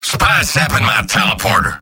Robot-filtered lines from MvM. This is an audio clip from the game Team Fortress 2 .
Engineer_mvm_autoattackedbyspy01.mp3